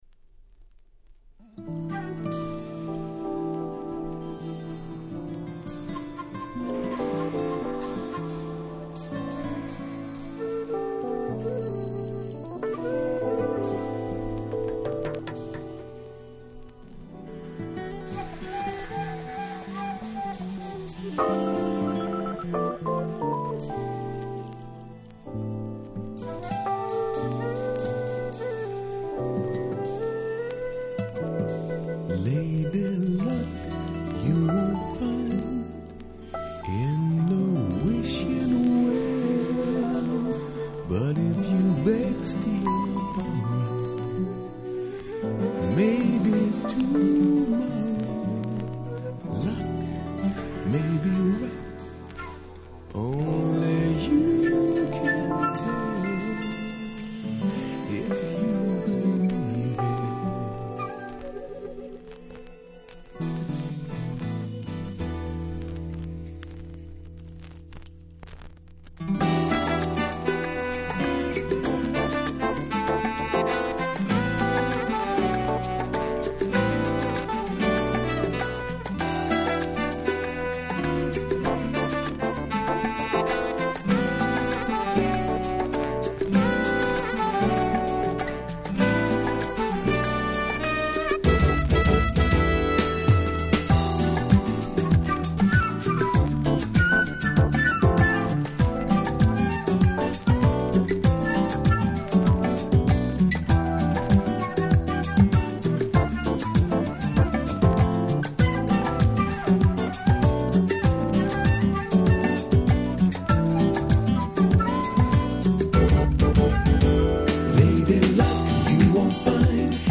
99' Nice UK Soul.
Bossa風味のBrazilian Beatが死ぬ程気持ちの良い素晴らしい1枚です！